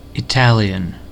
Ääntäminen
Synonyymit Italish Ääntäminen : IPA : [ɪ.ˈtæ.ljən] US : IPA : [ɪ.ˈtæ.ljən] derogatory: IPA : /ˌaɪˈtæljən/ Haettu sana löytyi näillä lähdekielillä: englanti Käännös Ääninäyte Substantiivit 1.